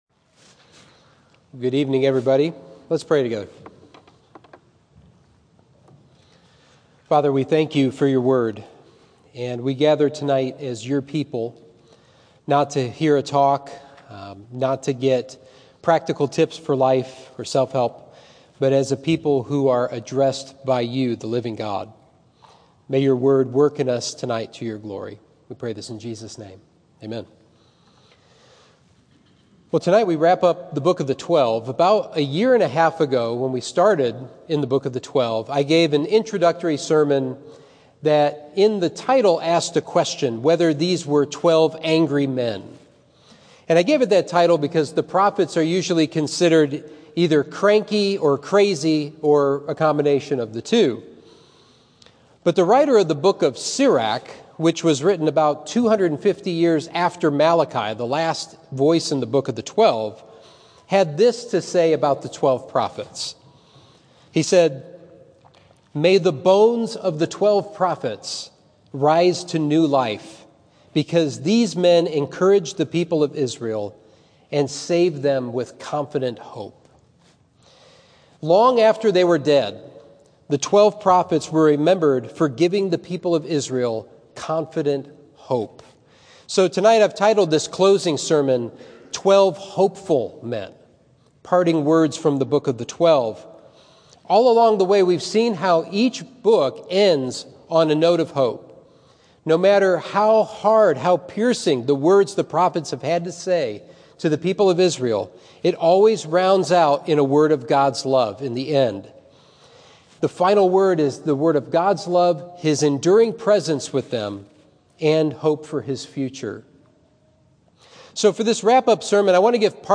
Sermon 2/20: Twelve Hopeful Men: Parting Words from the Book of the Twelve